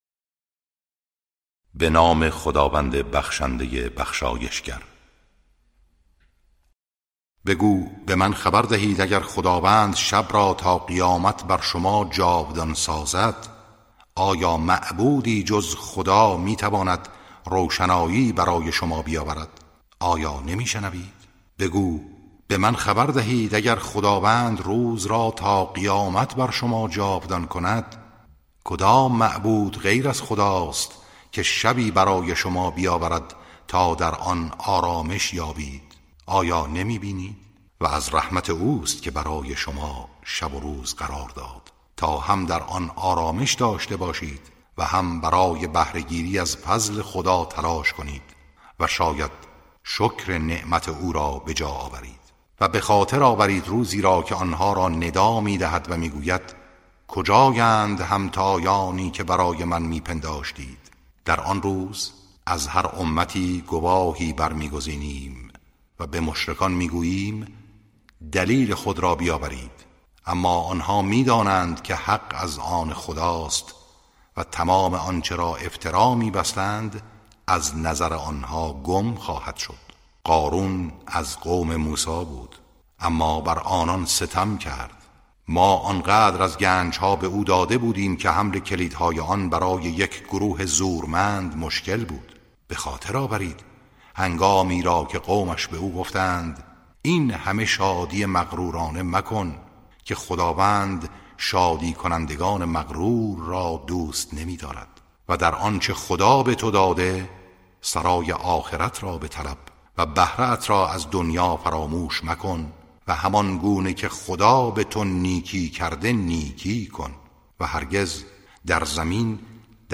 ترتیل صفحه ۳۹۴ سوره مبارکه قصص (جزء بیستم)